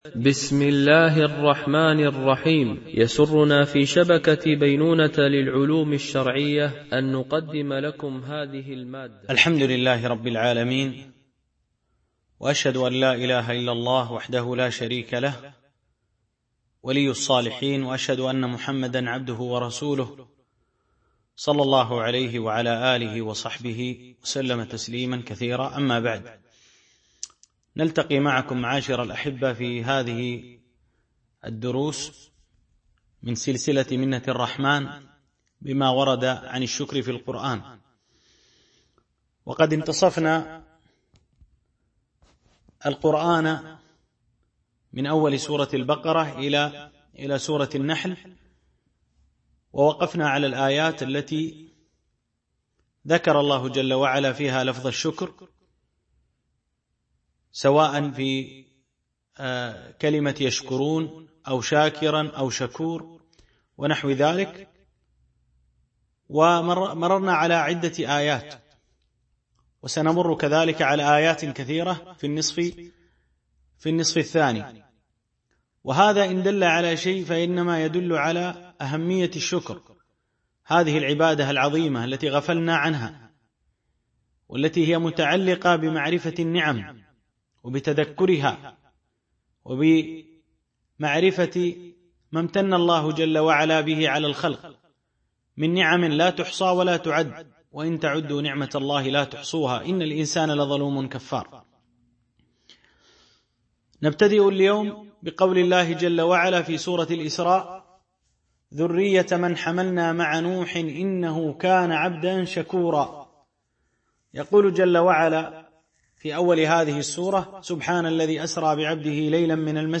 منة الرحمن بما ورد عن الشكر في القرآن ـ الدرس 6